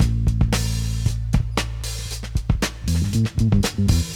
• 115 Bpm HQ Drum Beat A Key.wav
Free drum groove - kick tuned to the A note. Loudest frequency: 213Hz
115-bpm-hq-drum-beat-a-key-bul.wav